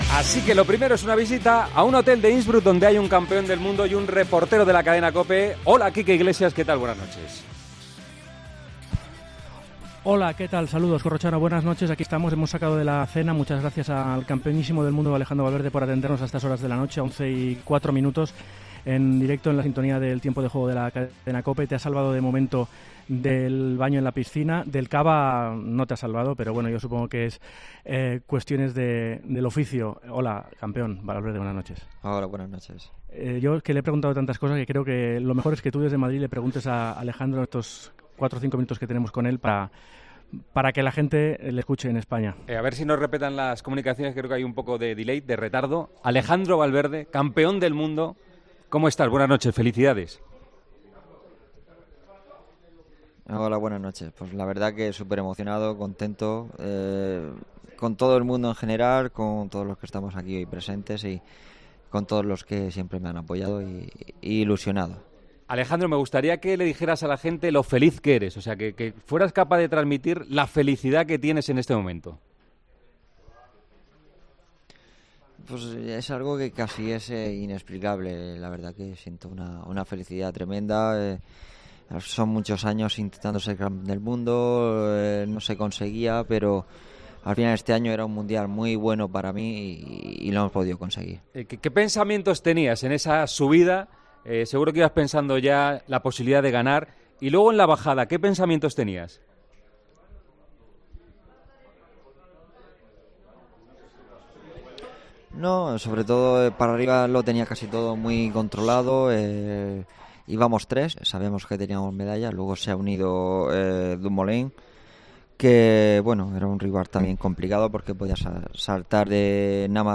Tiempo de Juego localizó este domingo al nuevo campeón del mundo de ciclismo, Alejandro Valverde, cenando pocas horas después de conquistar la medalla de oro: "Estoy súper emocionado, contento con todo el mundo que me ha apoyado, muy ilusionado. Es inexplicable, siento una felicidad tremenda, han sido varios años intentando ser campeón del mundo". confesó.